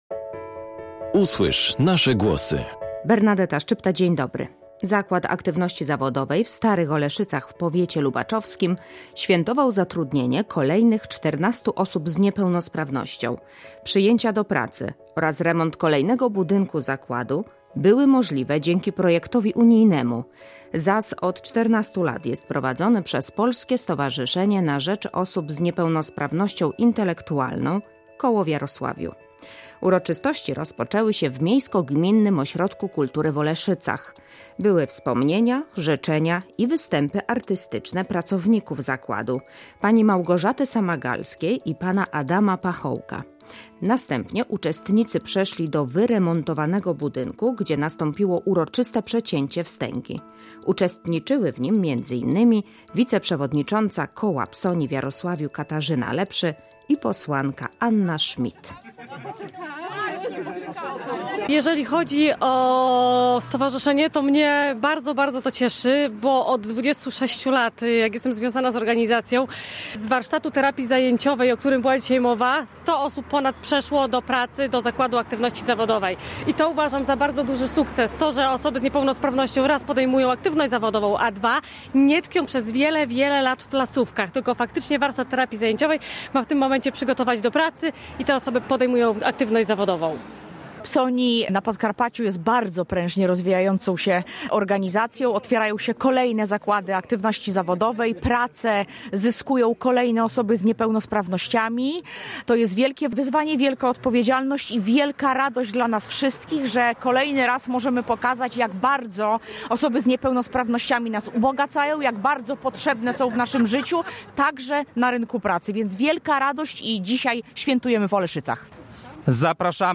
Zakład Aktywności Zawodowej w Starych Oleszycach w powiecie lubaczowskim świętował zatrudnienie kolejnych 14 osób z niepełnosprawnością. Przyjęcia do pracy oraz remont kolejnego budynku zakładu były możliwe dzięki projektowi unijnemu. ZAZ od 14 lat jest prowadzony przez Polskie Stowarzyszenie na rzecz Osób z Niepełnosprawnością Intelektualną Koło w Jarosławiu.